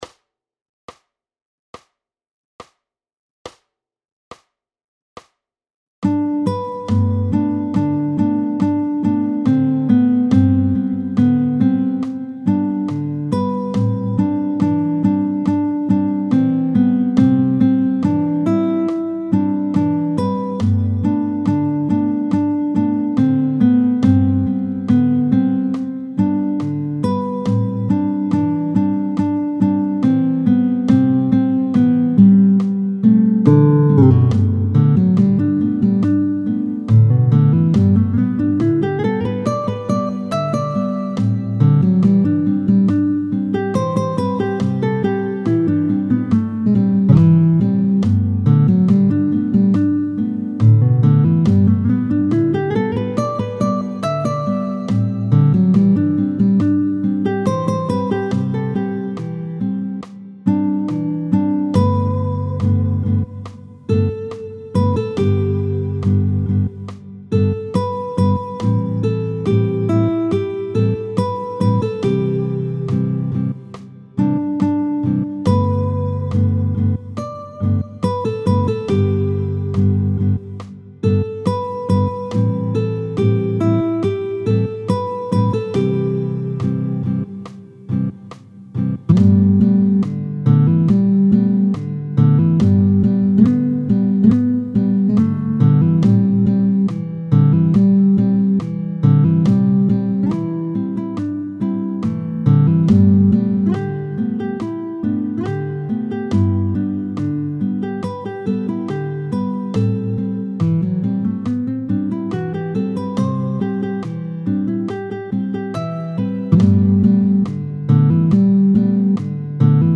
Nous sommes donc en Sol.
tempo 80